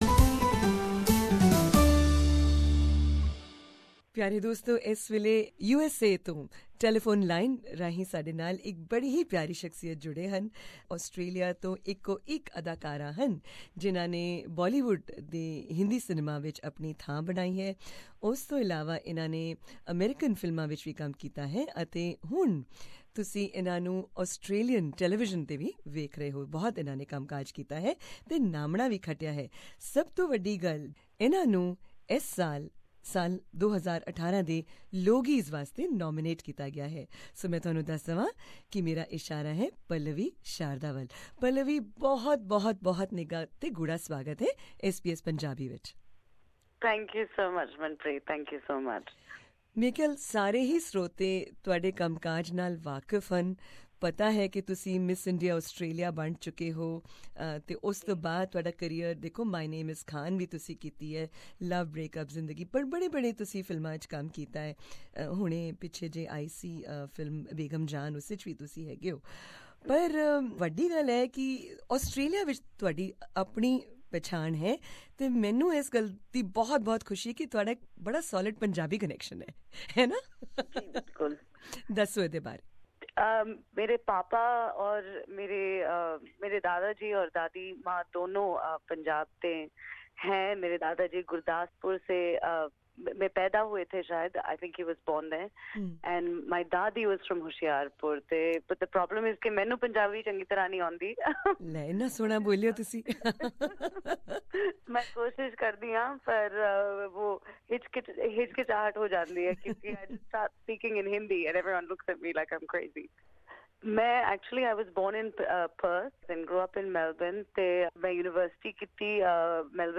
Actress Pallavi Sharda speaks to SBS Punjabi from USA, where she's shooting a pilot for an American television series, and talked about her Logies nomination.